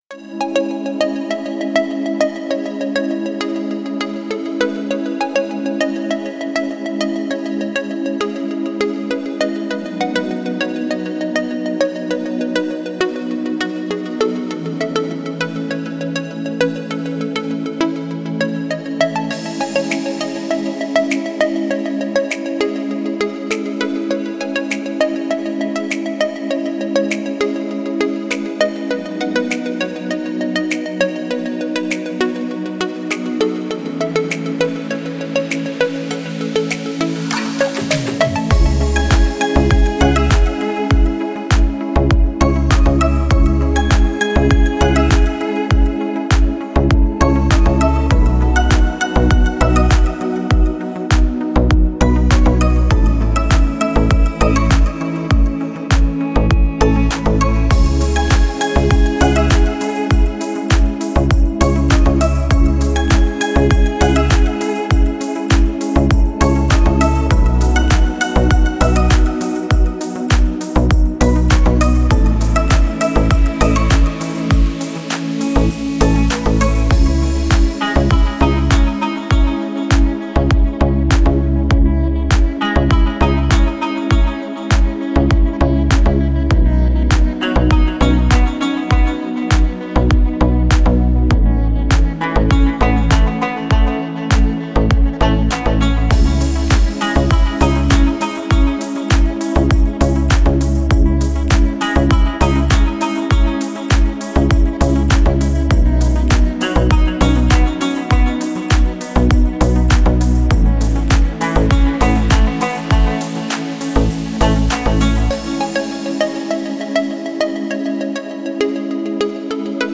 красивая музыка без слов